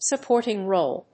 音節suppòrting róle